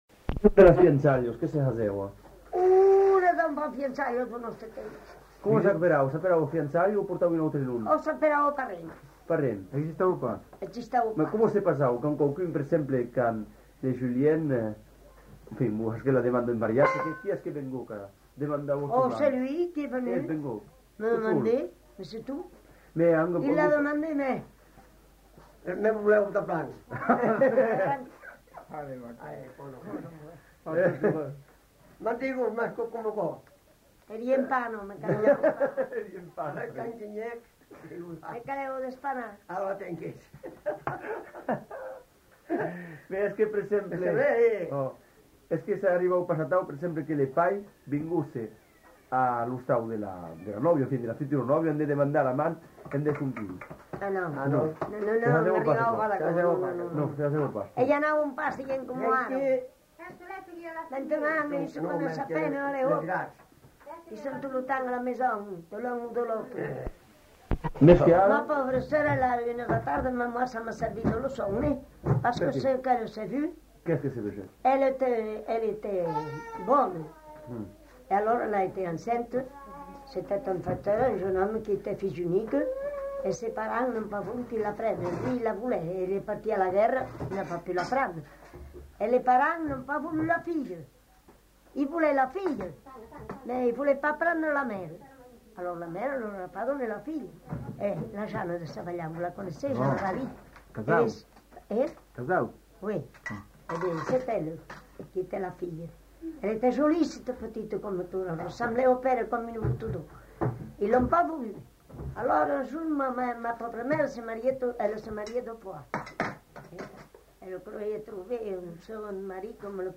Lieu : Puylausic
Genre : témoignage thématique